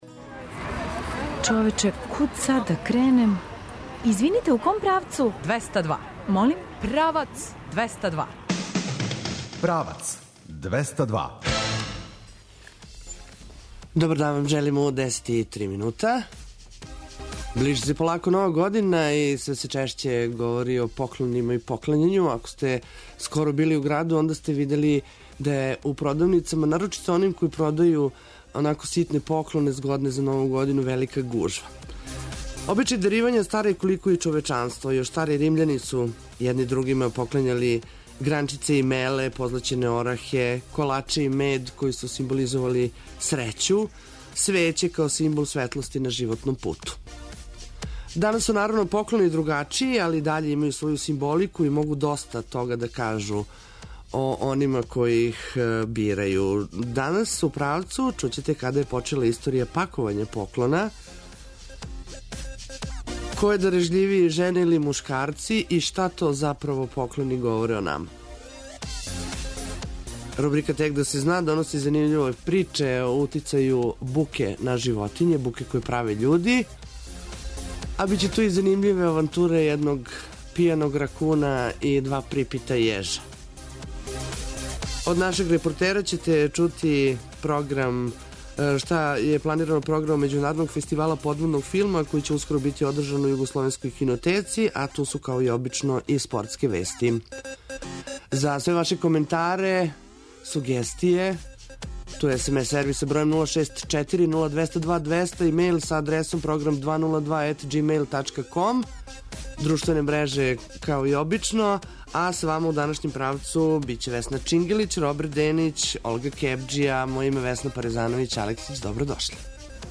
Ту су и спортске вести.